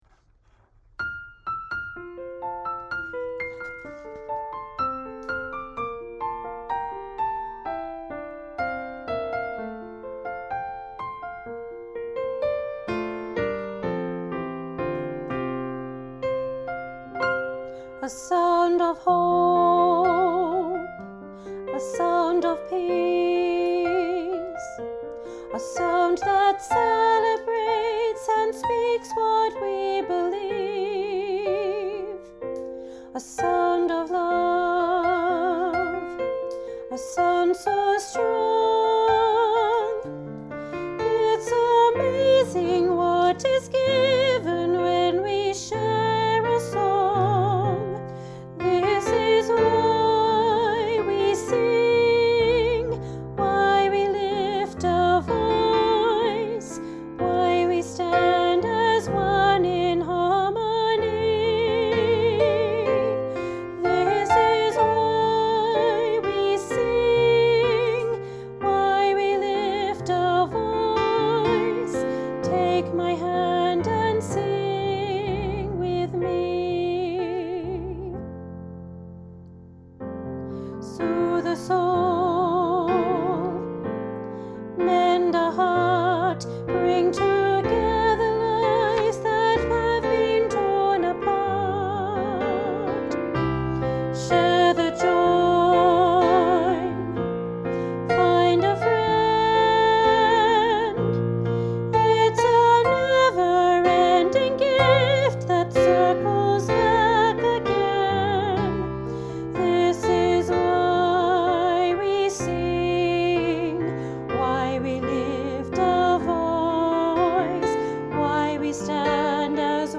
Why-we-sing-Part-2_Elem-with-opening-solo-for-reference.mp3